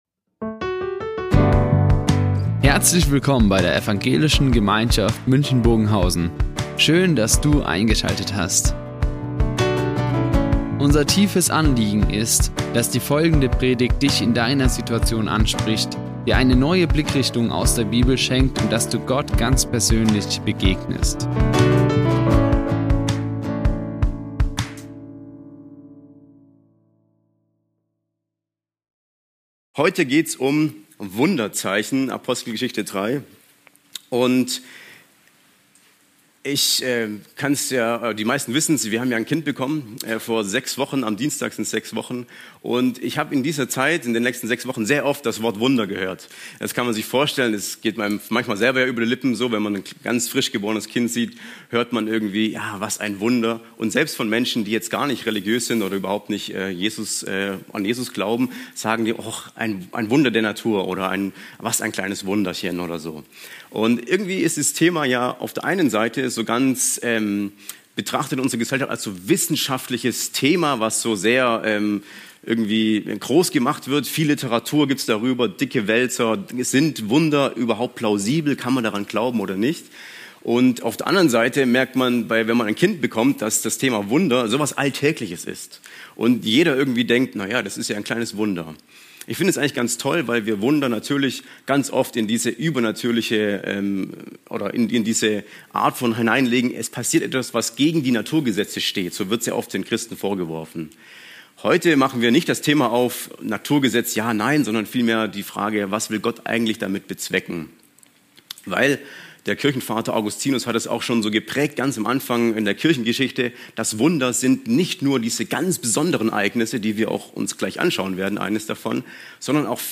Wunderzeichen | Predigt Apostelgeschichte 3,1-10 ~ Ev. Gemeinschaft München Predigten Podcast
Die Aufzeichnung erfolgte im Rahmen eines Livestreams.